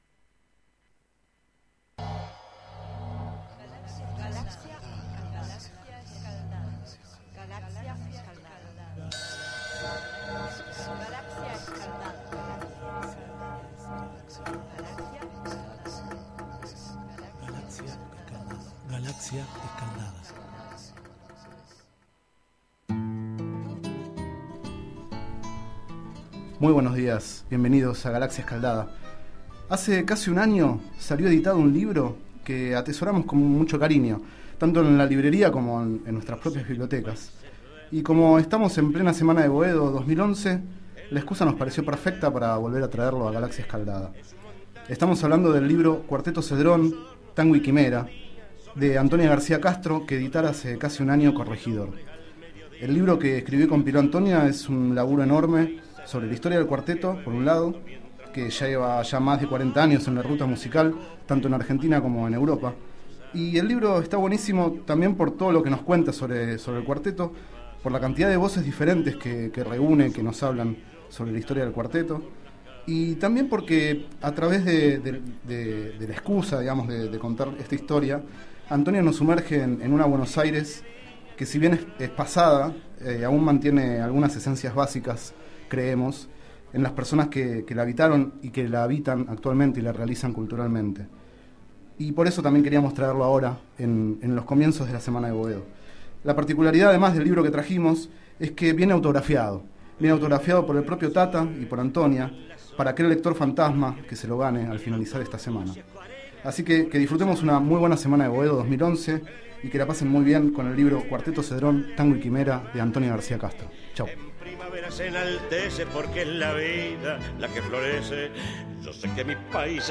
Este es el 27º micro radial, emitido en los programas Enredados, de la Red de Cultura de Boedo, y En Ayunas, el mañanero de Boedo, por FMBoedo, realizado el 17 de septiembre de 2011, sobre el libro Cuarteto Cedrón: Tango y quimera, de Antonia García Castro.
Durante el micro escuchamos Yo sé que mi país es verde del Cuarteto Cedrón, Para que vos y yo (1997).